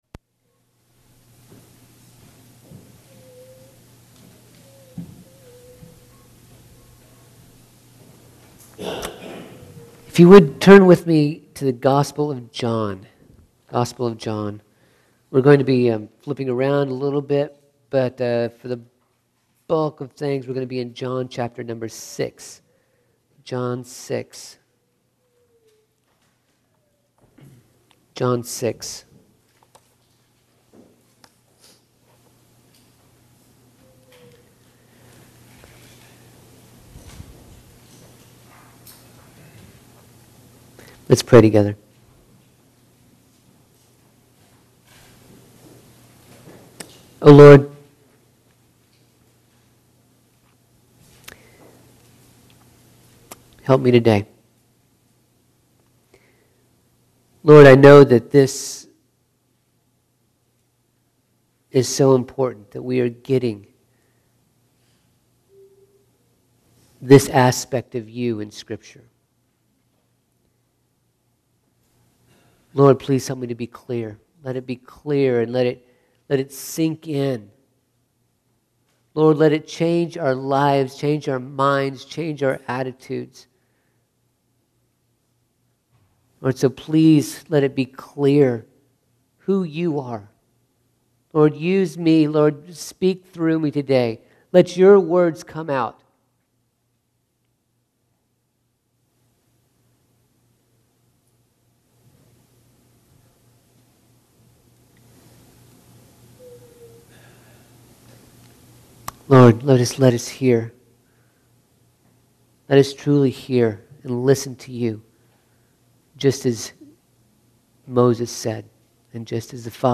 These messages were preached at Franklin Congregational Church in Franklin, CT.